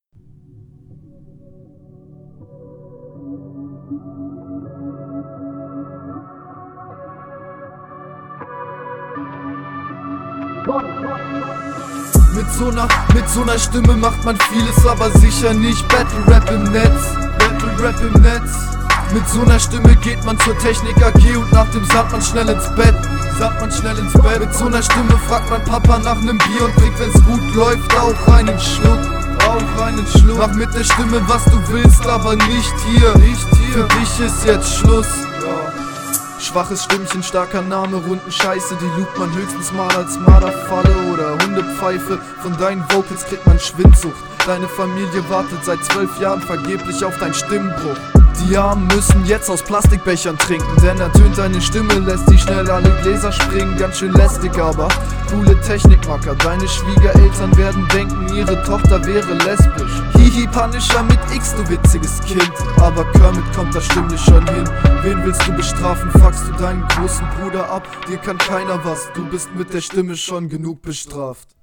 Dieses Lines wiederholen klingt echt komisch, n richtiger Automatisierter Delay wäre deutlich geiler.